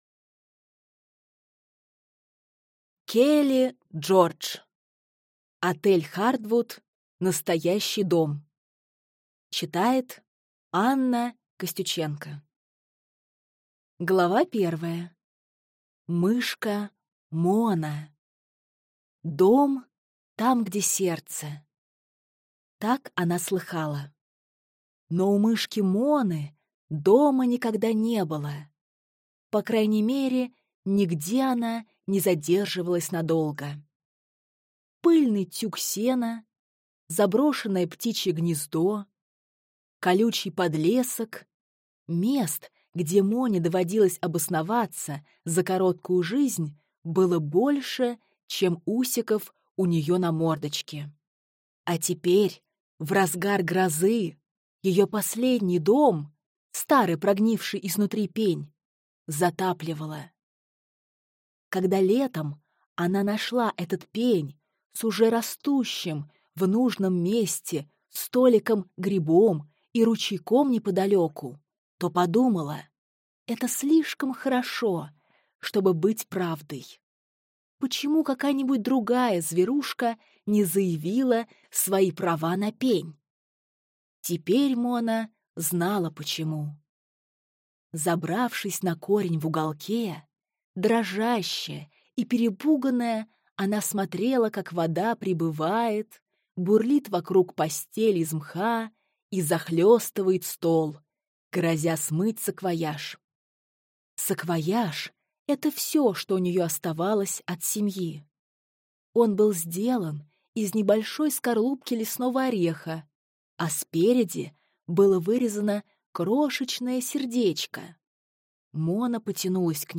Аудиокнига Отель Хартвуд. Настоящий дом | Библиотека аудиокниг